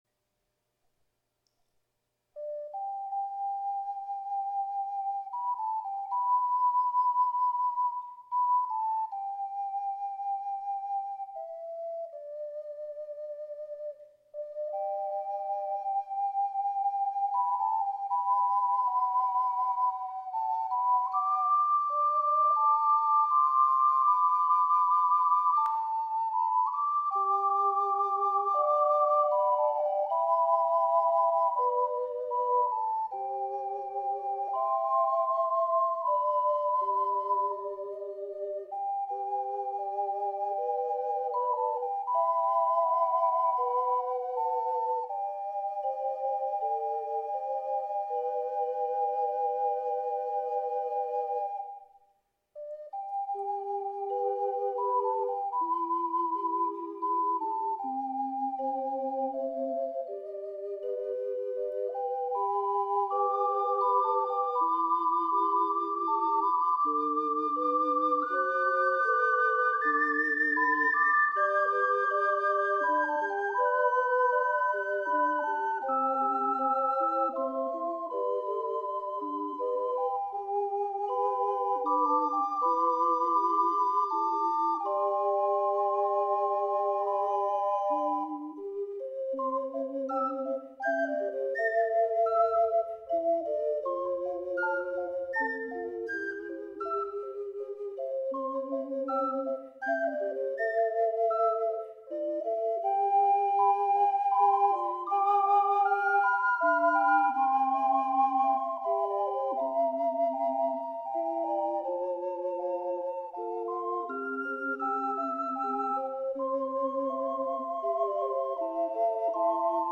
四重奏
(ジャズ・アレンジ付）   ①AC+SF
④BC   ４拍子→３拍子→３拍子→ジャズ風４拍子と変化します。
最後のジャズ・アレンジが楽しいです。